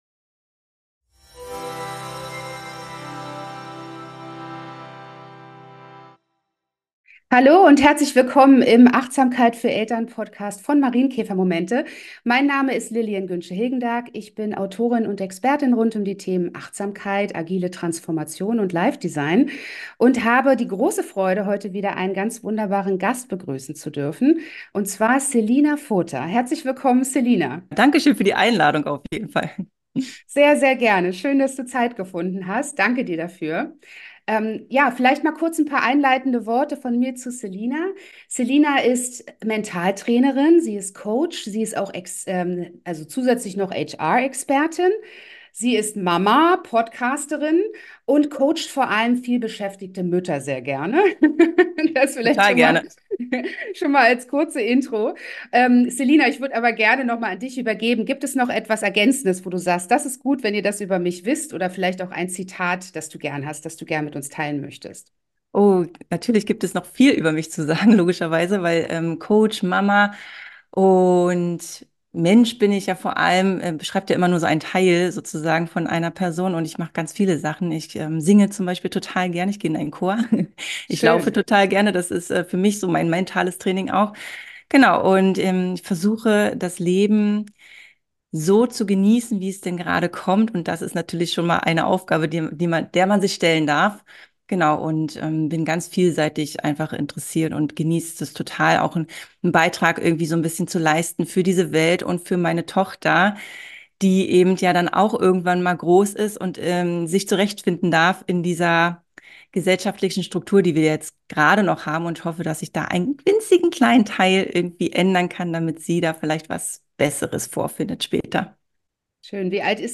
im Talk